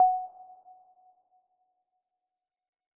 harmony2 - Dream Sounds: Harmony 2 sound theme for KDE Plasma
message-new-instant.ogg